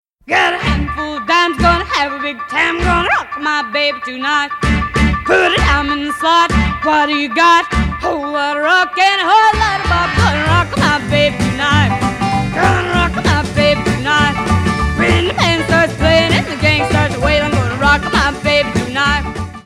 which has so many growls as to sound forced